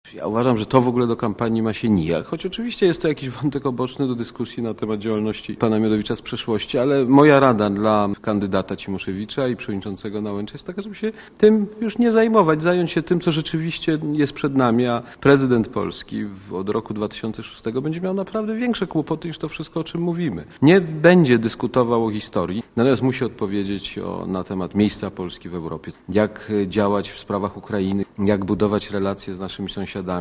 Mówi Aleksander Kwaśniewski